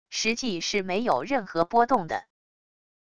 实际是没有任何波动的wav音频